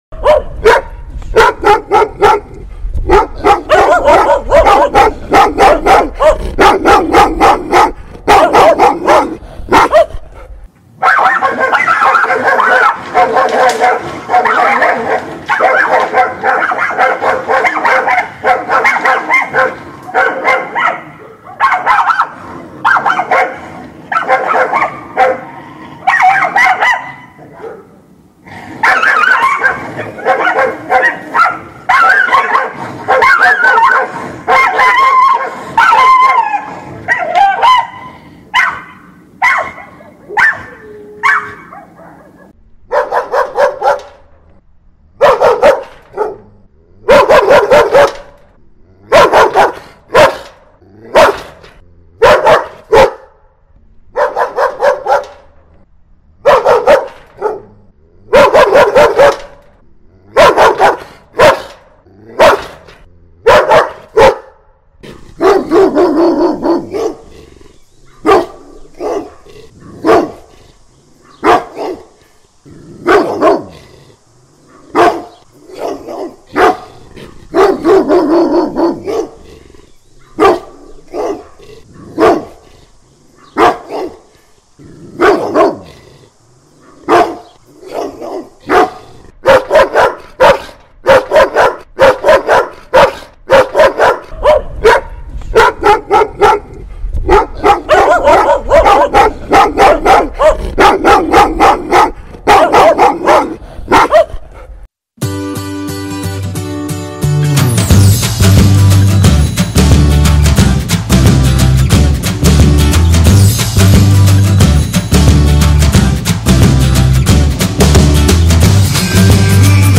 angry dog